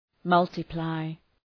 Προφορά
{‘mʌltı,plaı}